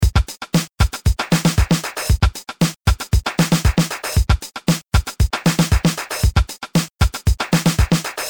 Tre trummaskiner, dom rætta svaren!
Observera att inga nivåjusteringar har gjorts och att maskin C har editerbara ljud och dessutom kan ljud får på EPROM.
Alla ljud är samlingar och sequensade i Tunafish.
Att kalla 10 sek klipp med enbart trummor för "låtar" och "extremsunk" känns som en kraftig överdrift.